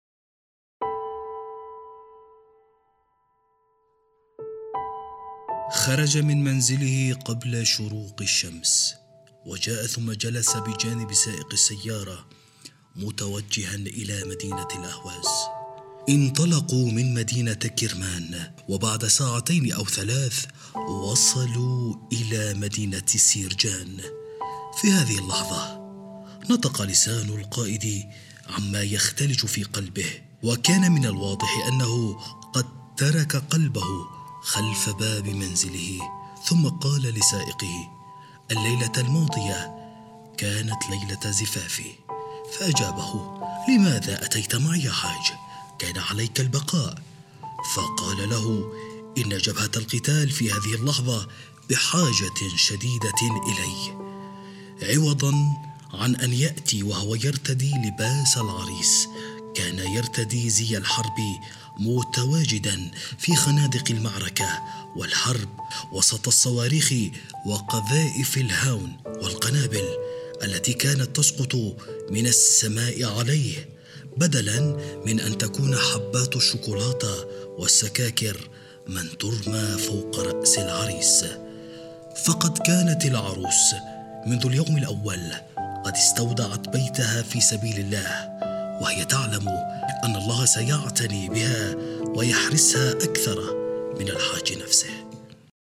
المصدر الصوتي :لكلمتة أثناء الحفل التأبيني الكبير ياس نبوي